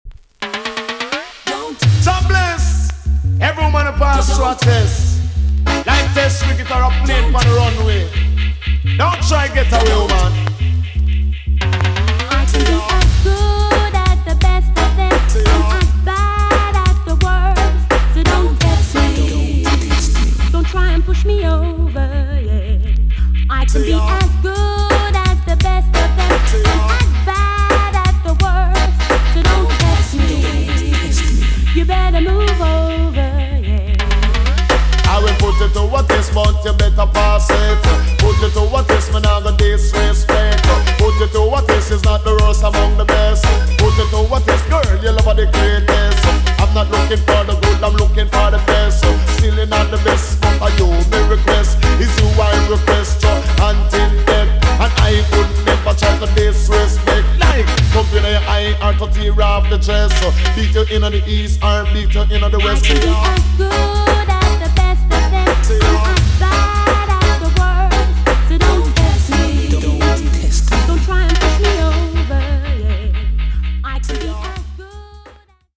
EX- 音はキレイです。
1990 , RARE , WICKED DANCEHALL TUNE!!